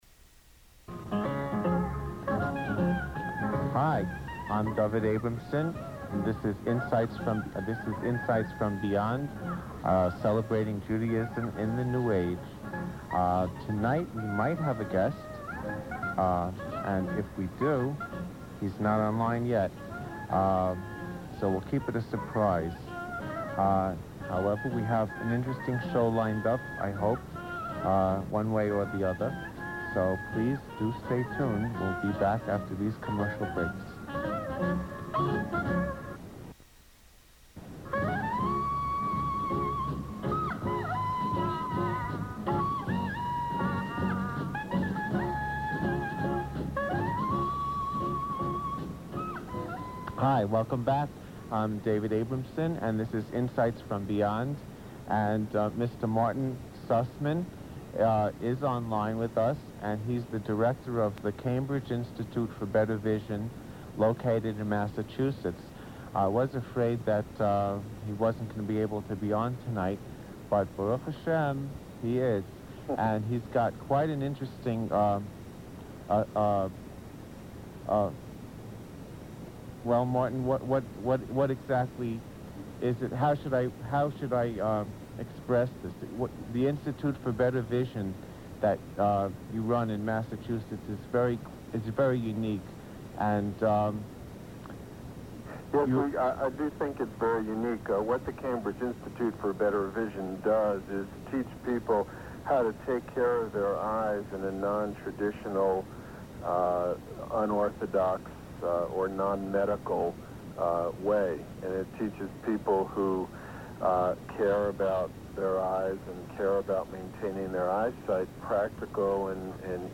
..from the radio broadcast Insights from Beyond.